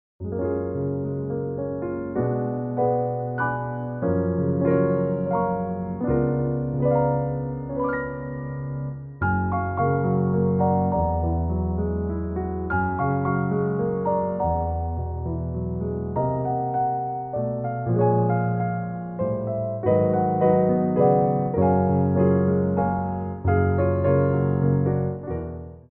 Développés
3/4 (8x8)